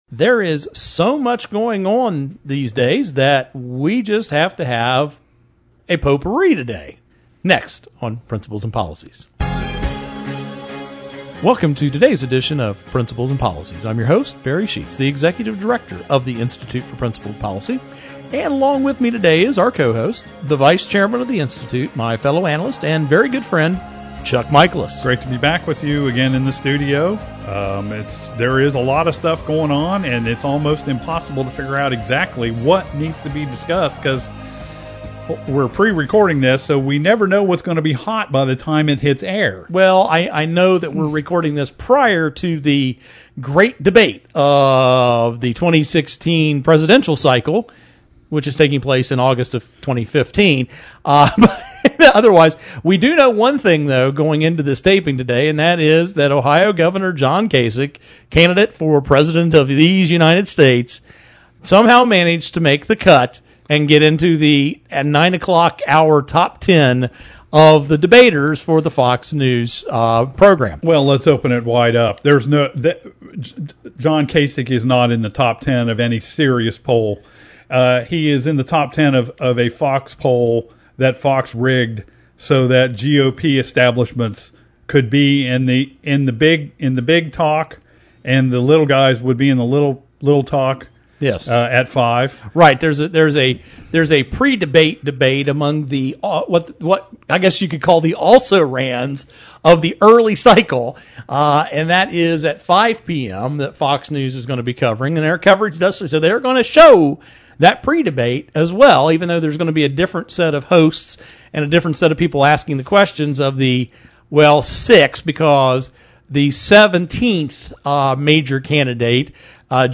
Our Principles and Policies radio show for Saturday August 15, 2015.